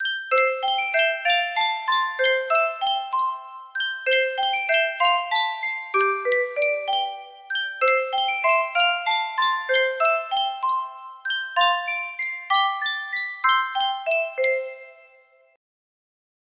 Sankyo Music Box - My Bonnie Lies Over the Ocean LAG music box melody
Full range 60